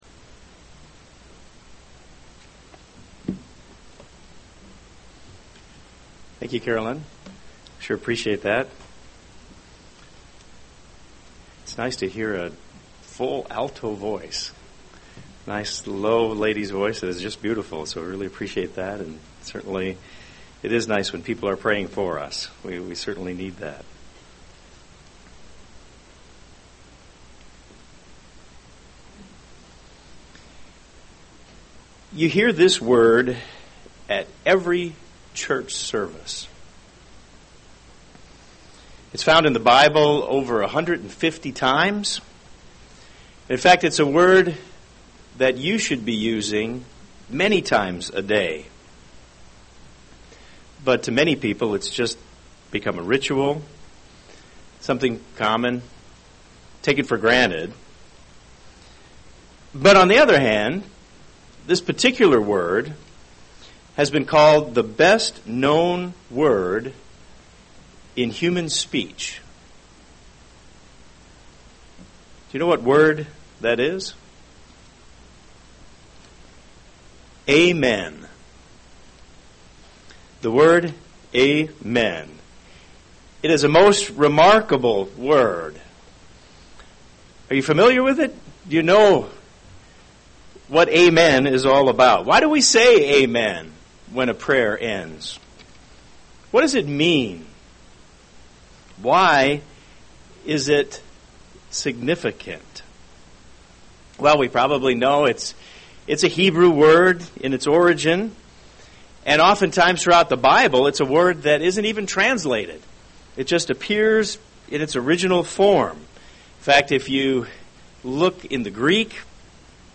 .. UCG Sermon Transcript This transcript was generated by AI and may contain errors.